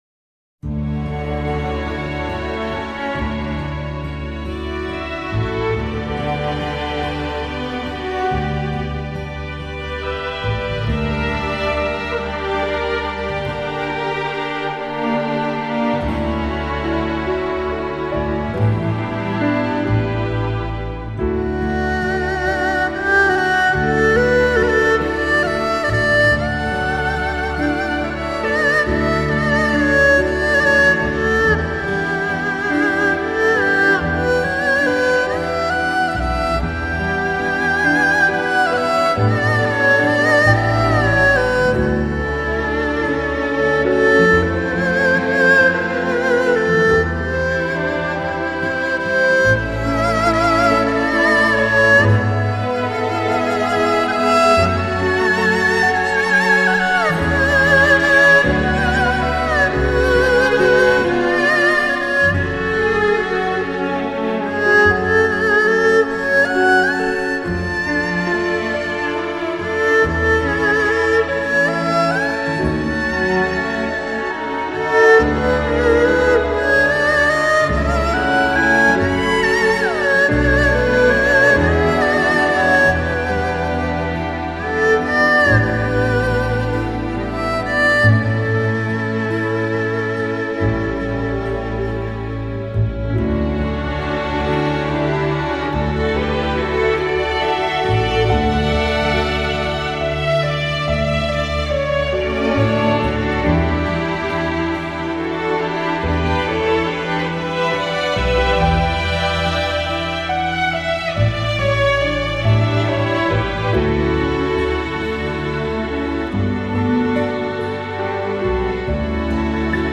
少しヴィヴゥラートがかかった二胡の主旋律が流れ出す。
比小提琴的声音更加圆润柔美的二胡的主旋律流淌出来。